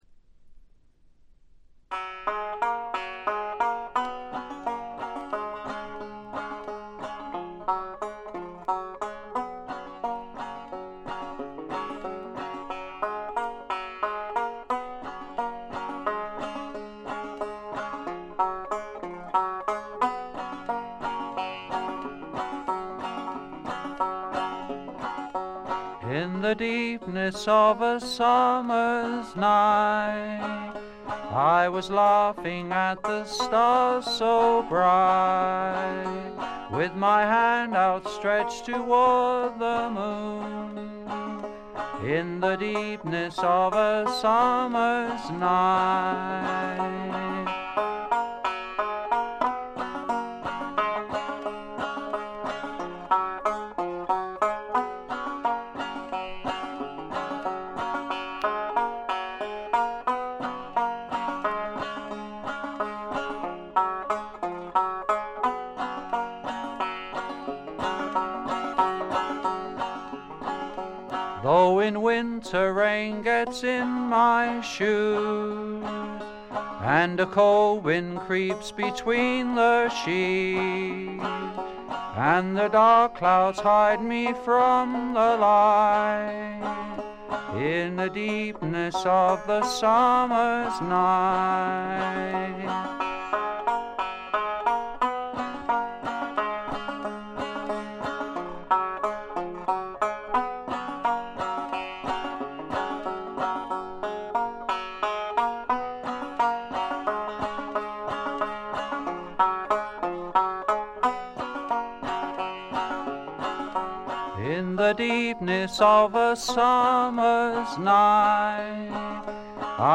基本はバンジョーをバックにした弾き語りで、訥々とした語り口が染みる作品です。
試聴曲は現品からの取り込み音源です。